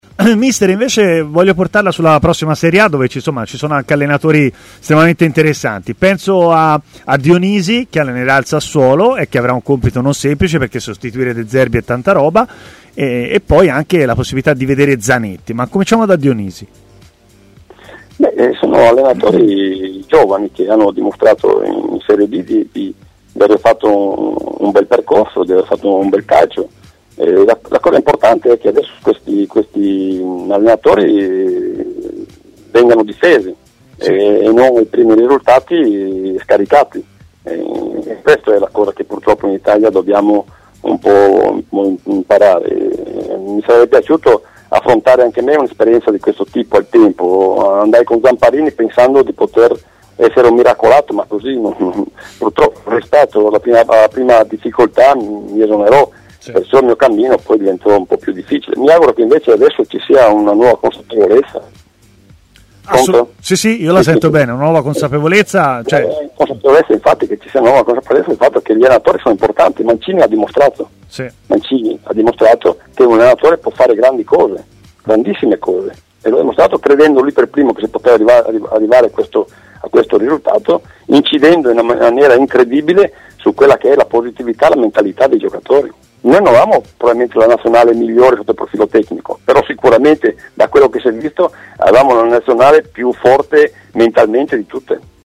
ha parlato a TMW Radio, intervenendo nel corso della trasmissione Stadio Aperto